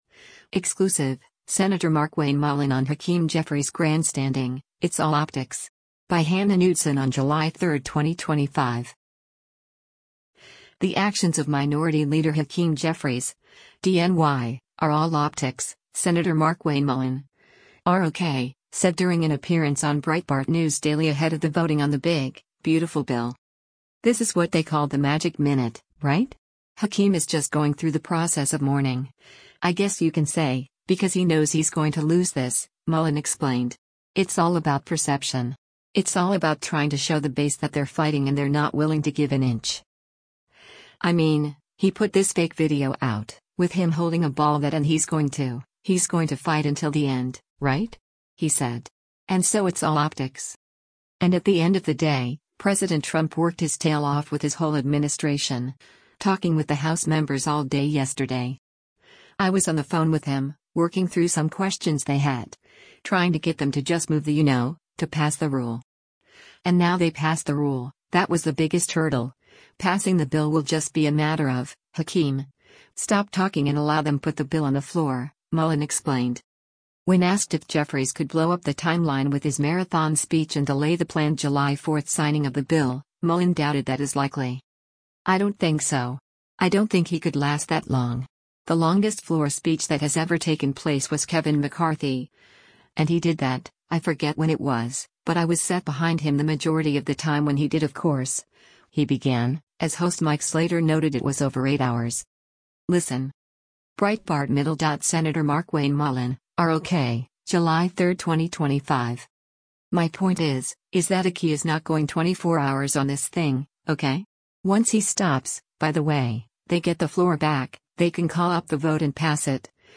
The actions of Minority Leader Hakeem Jeffries (D-NY) are all “optics,” Sen. Markwayne Mullin (R-OK) said during an appearance on Breitbart News Daily ahead of the voting on the “big, beautiful bill.”